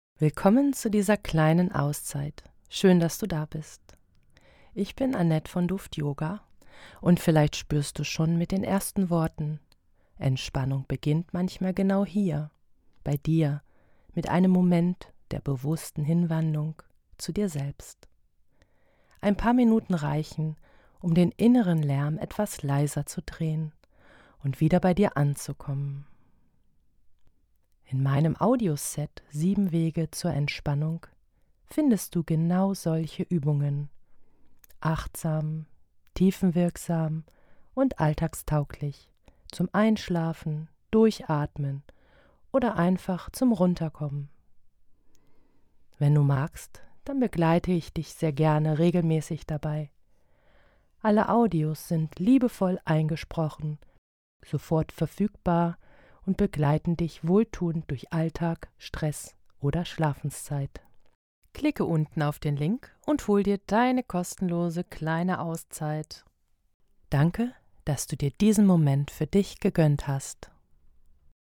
Hol dir geführte Entspannung als MP3 (download) – für weniger Stress, besseren Schlaf & mehr innere Ruhe. Ideal für zwischendurch oder zum Einschlafen.